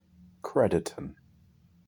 Captions English Pronunciation